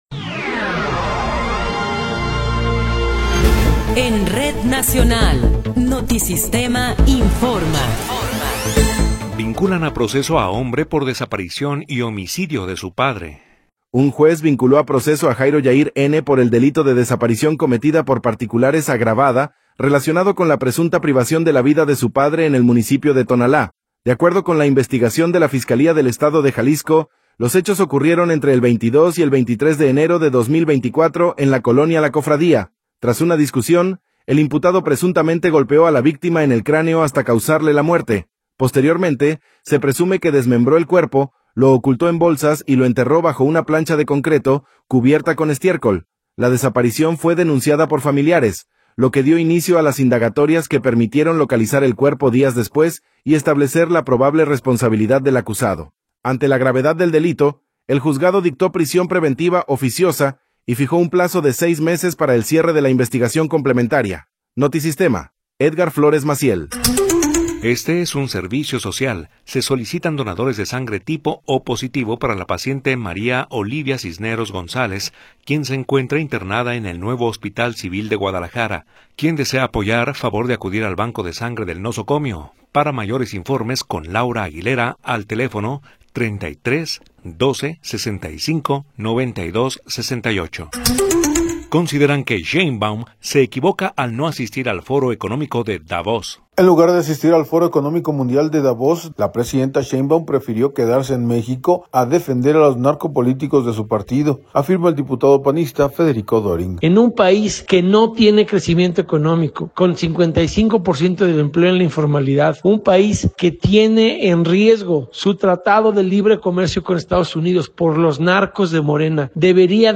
Noticiero 19 hrs. – 16 de Enero de 2026
Resumen informativo Notisistema, la mejor y más completa información cada hora en la hora.